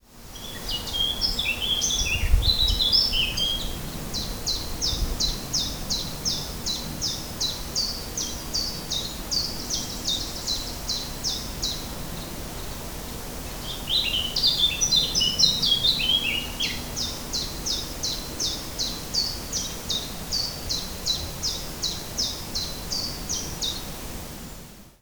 Overhead, a blackcap (sometimes known as the ‘northern nightingale) and a chiffchaff politely take turns to entertain us (play sound file below).
blackcap-and-chiffchaff.ogg